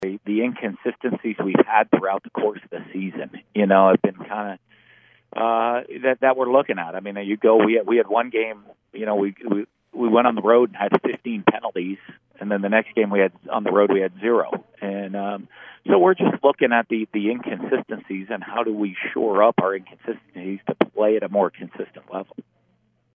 On Wednesday, Gator football head coach Dan Mullen spoke to the press for the first time since Saturday’s 49-42 loss against LSU.